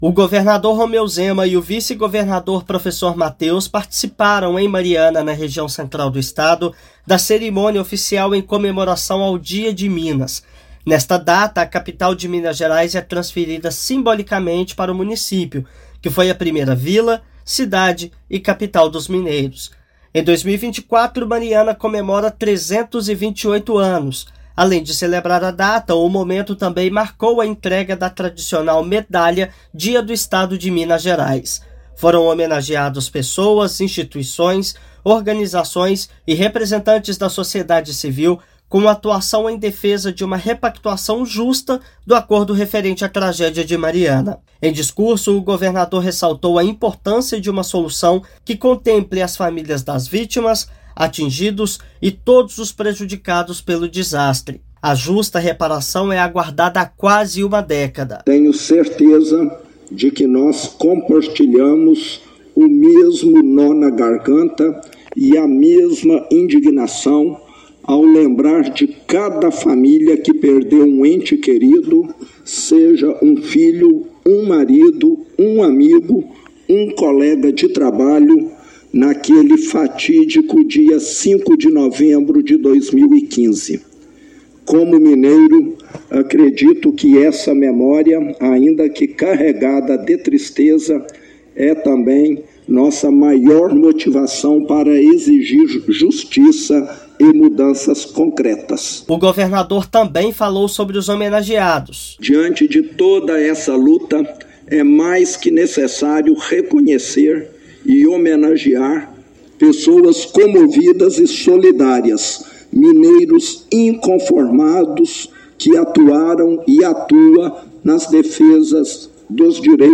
Governador e vice-governador enfatizaram a luta do Governo de Minas para que a população e as cidades recebam uma reparação justa. Ouça matéria de rádio.